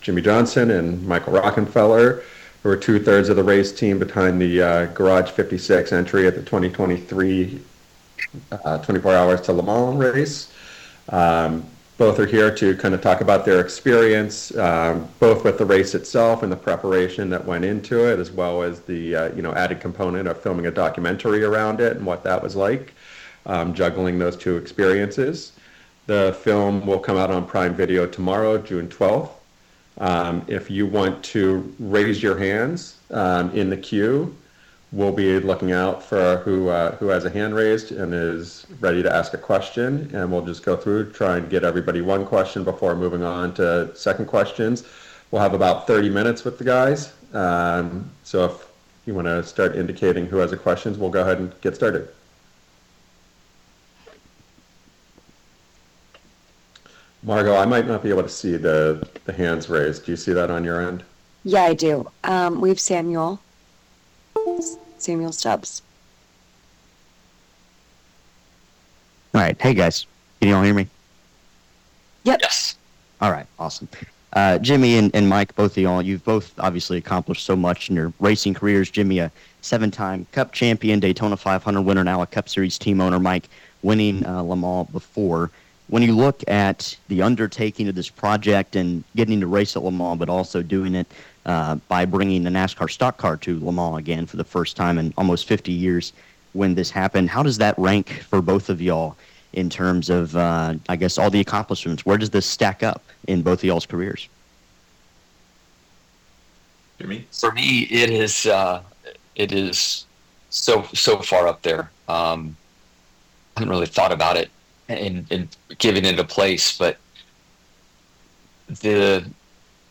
Jimmie Johnson talks to the Media about American Thunder
Jimmie Johnson & Michael Rockenfeller, Amazon Prime’s Documentary NASCAR American Thunder Zoom Press Conference June 11, 2025